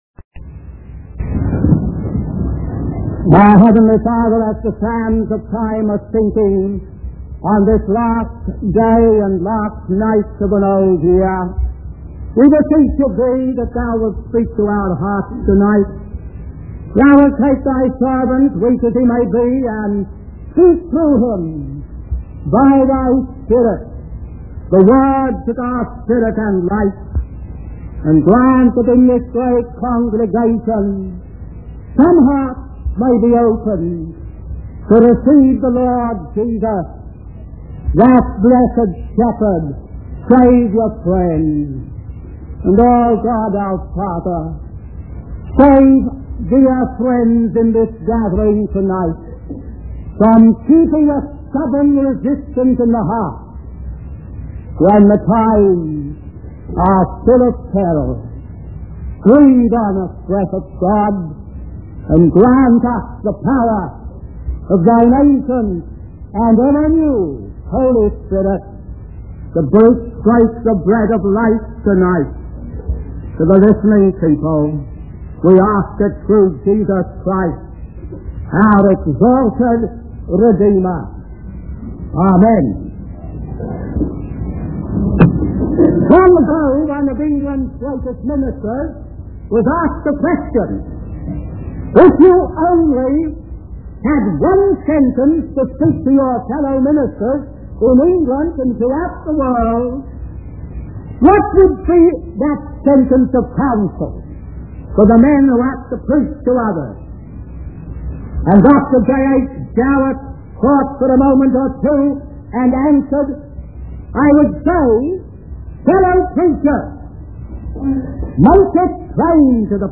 In this sermon, the preacher begins by discussing the importance of passing on the truth of God's word. He emphasizes the need for faith and confession in order to anchor one's soul in the faithfulness of God.